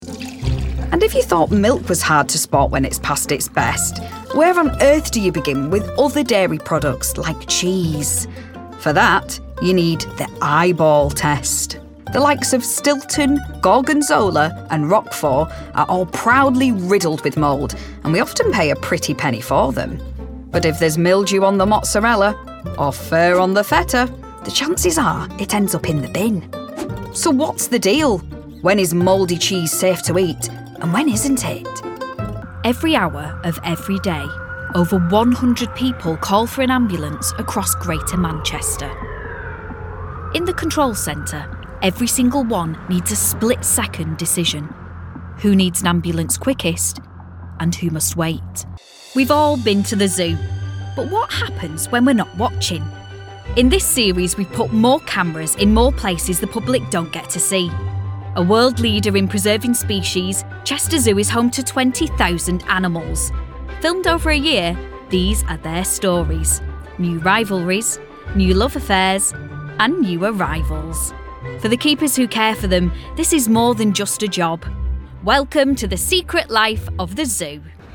30s-40s. Female. Lancashire. Studio
Narration